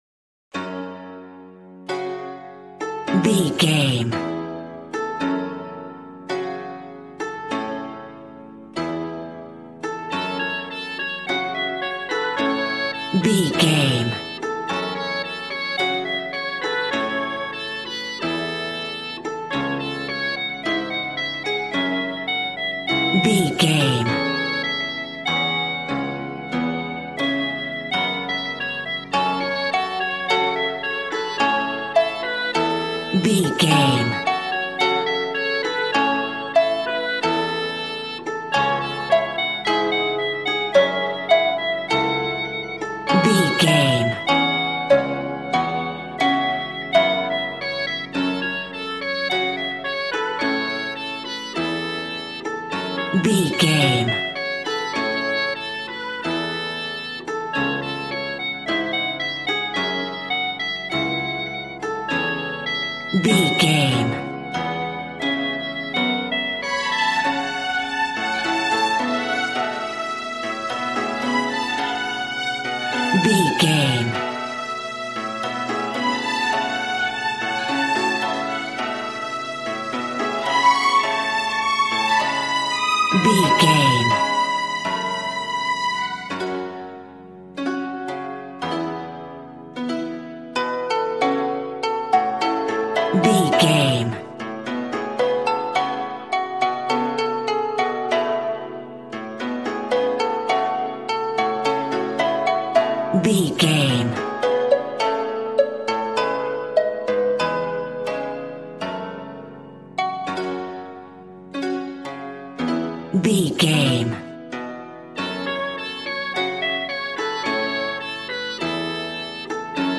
Ionian/Major
happy
bouncy
conga